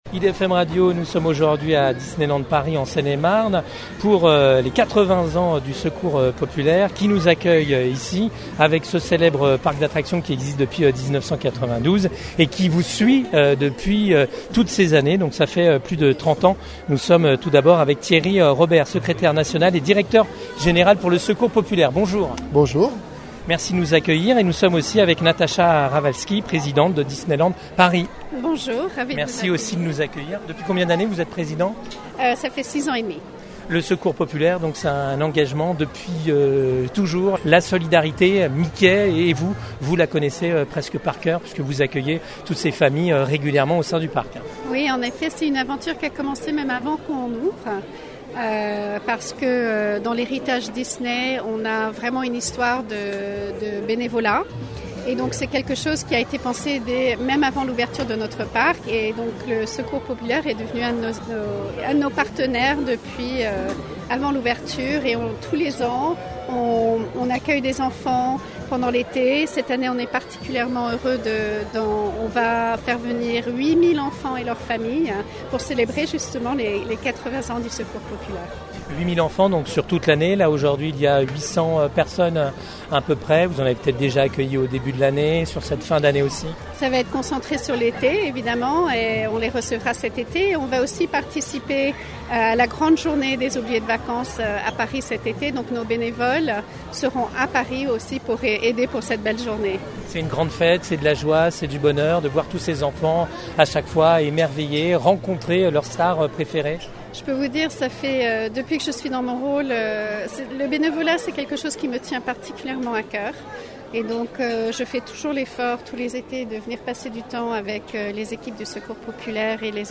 Notre reportage à Marne-la-Vallée.